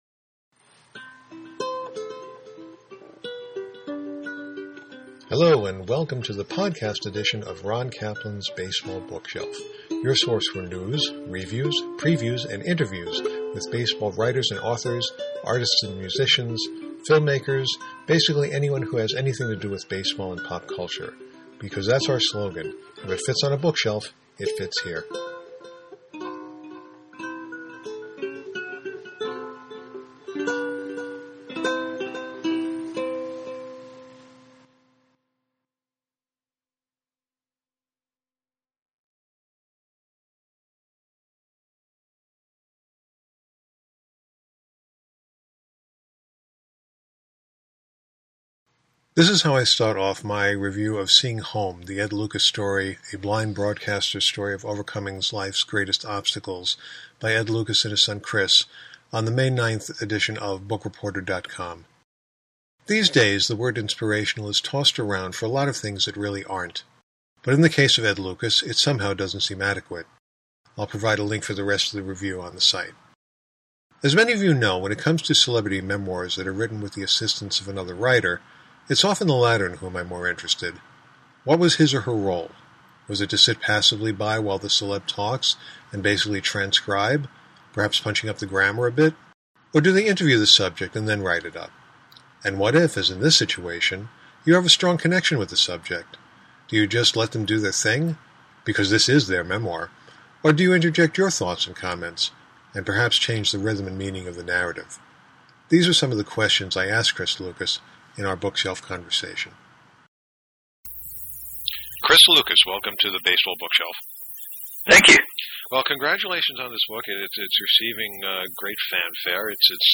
Bookshelf Conversation